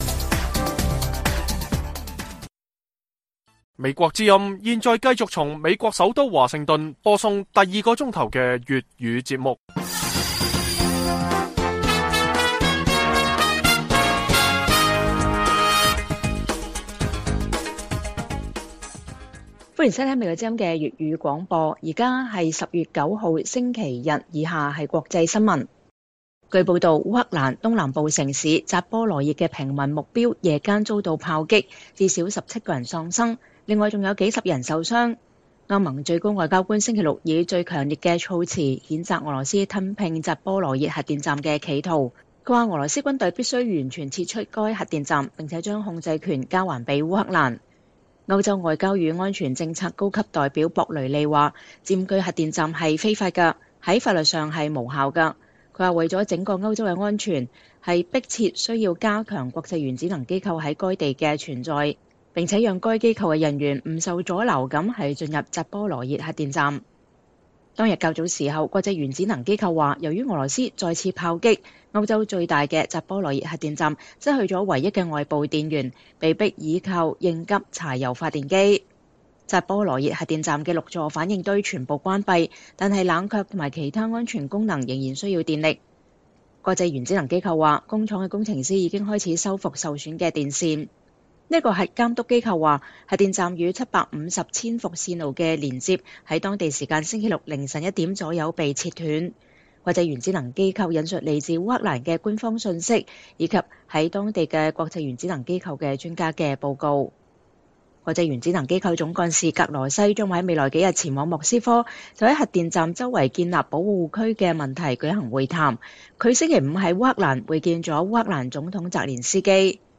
粵語新聞 晚上10-11點: 烏克蘭在東部重鎮利曼市又發現一處大型亂葬崗